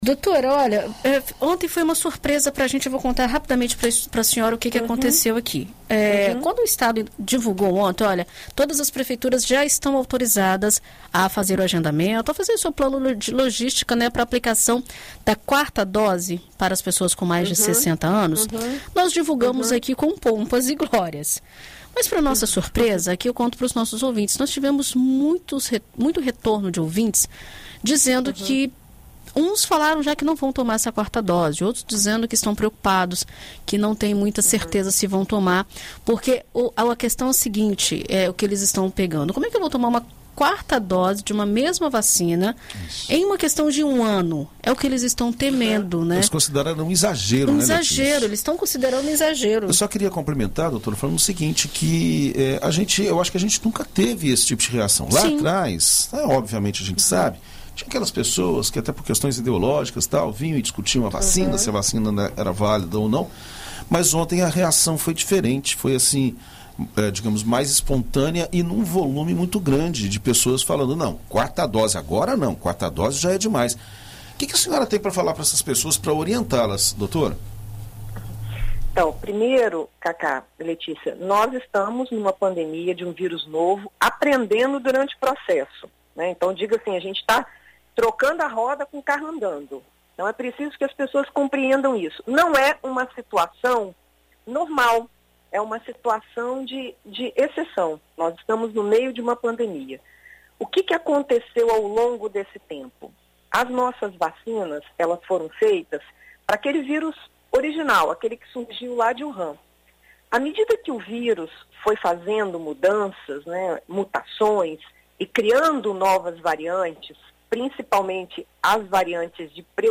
Para sanar essa dúvida, a epidemiologista e professora da Universidade Federal do Espírito Santo, Ethel Maciel, explicou na rádio BandNews FM Espírito Santo a necessidade de quatro doses, principalmente para a população idosa.